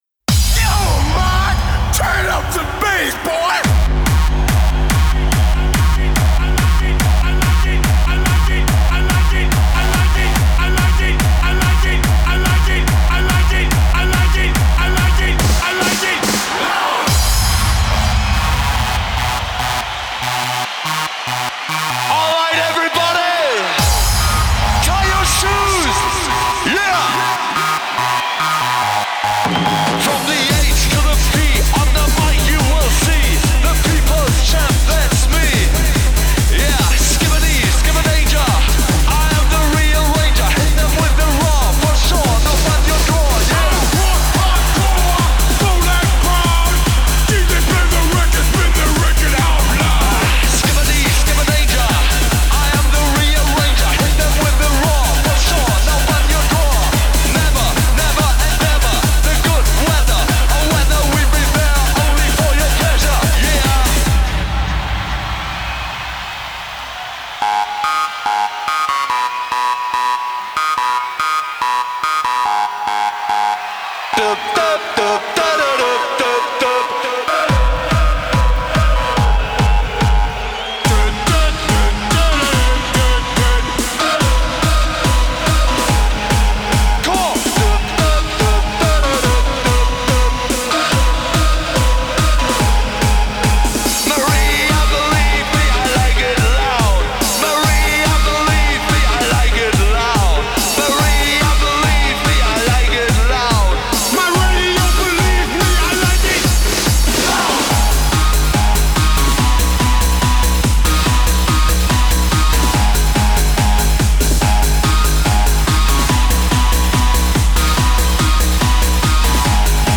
Techno 90er